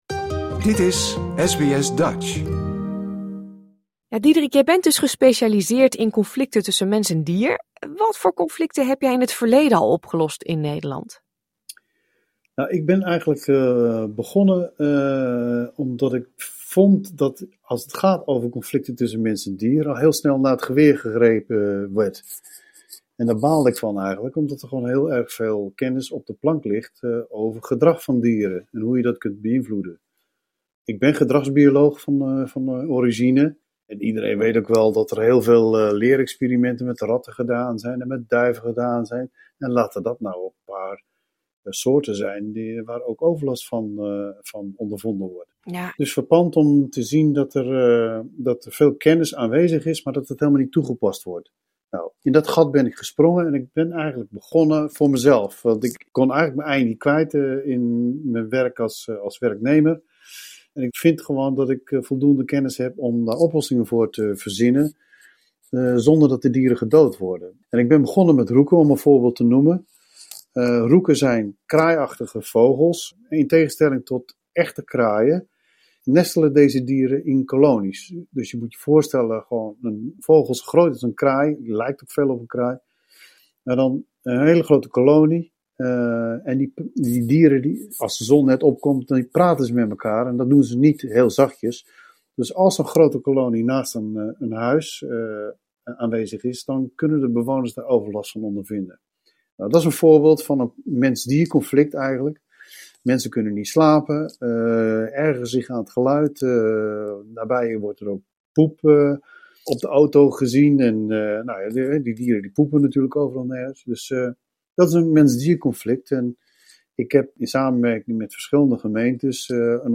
gedragsbioloog en wolvenexpert